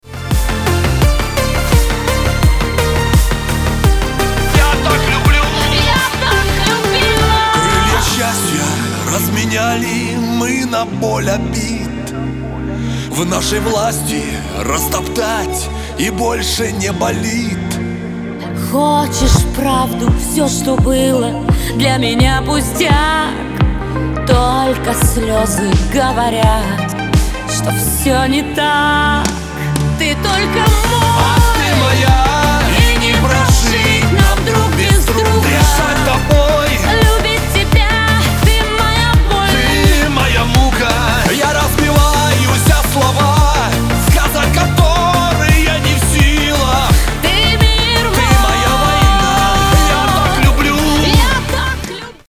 • Качество: 320, Stereo
русский шансон
vocal